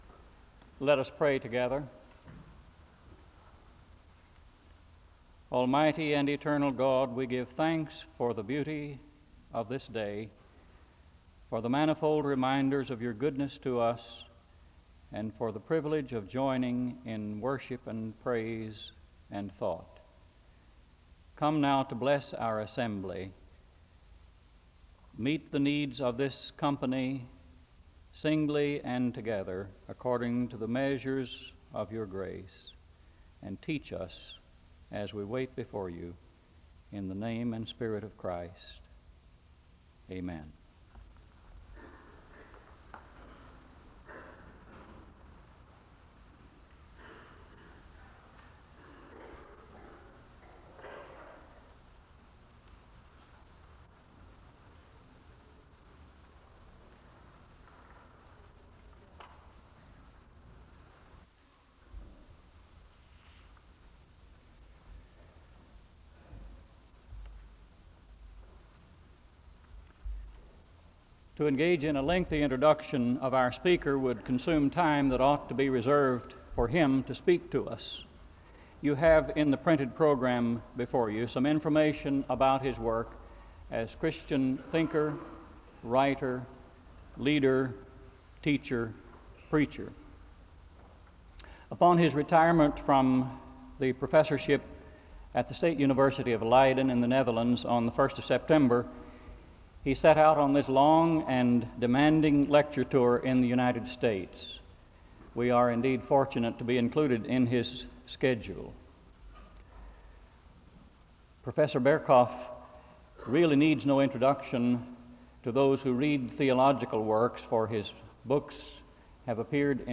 The service begins with a word of prayer (00:00-00:42). Hendrikus Berkhof is introduced as the special lecturer (00:43-02:58). Berkhof begins his lecture by tracing the development of the study of theology in the early 20th century, and he asks the question of what will be the new method that comes in the latter half of the 20th century (02:59-20:13). Berkhof spends most of his lecture examining the rise of experiential theology, and he argues, through the examination of figures such as Paul Tillich and Karl Barth, that there should be dialogue about experience when looking at theology (20:14-52:18). The service ends with a benediction (52:19-52:59).